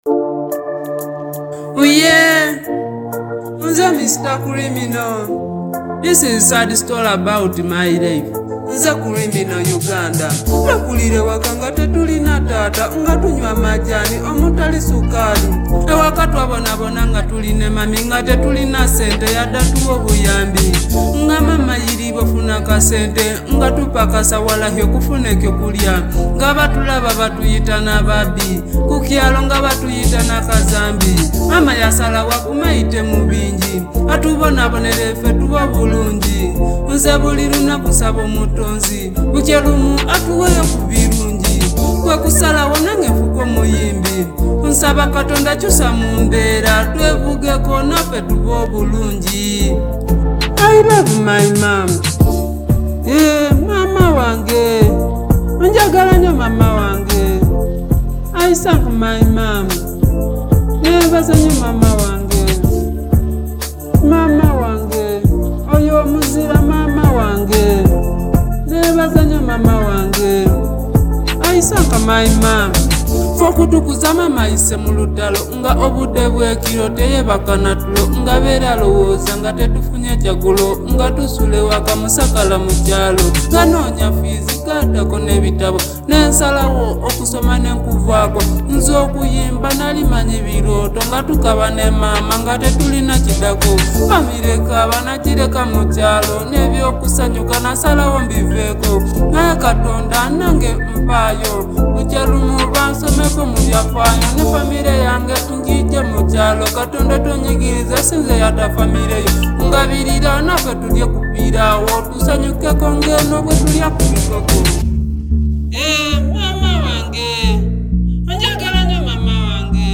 Genre: Rap Music